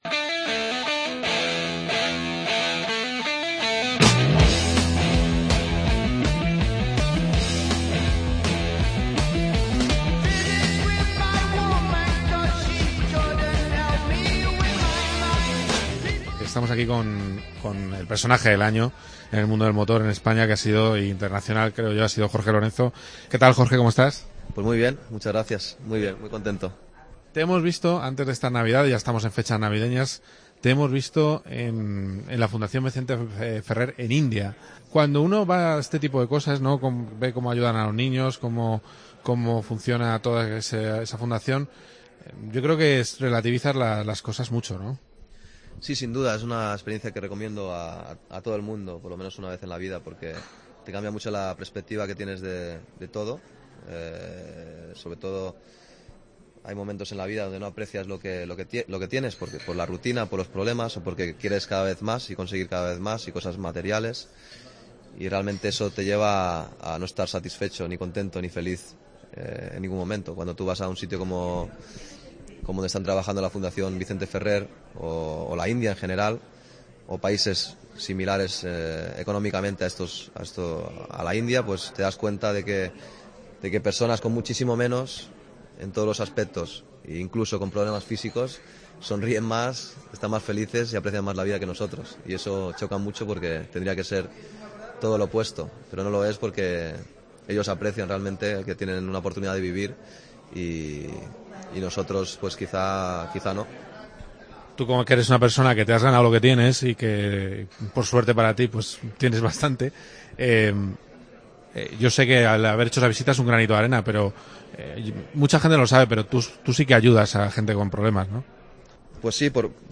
Entrevista a Jorge Lorenzo, en COPE GP